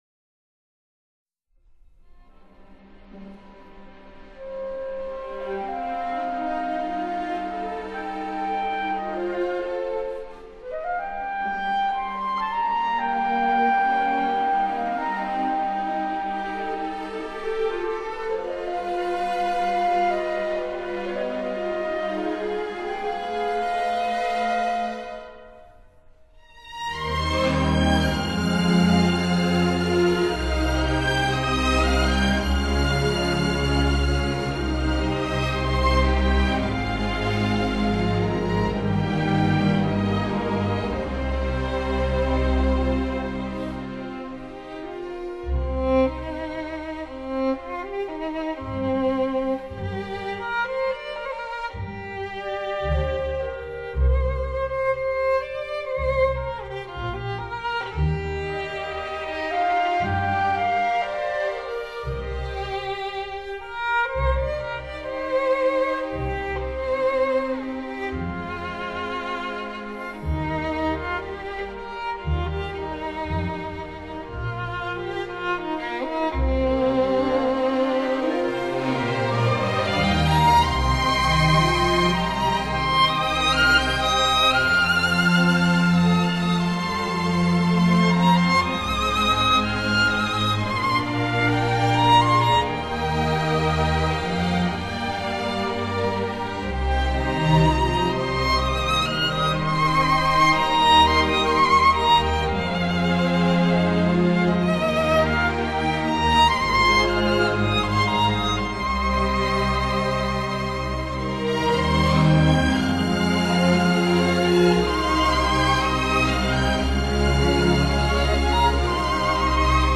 发烧录音专辑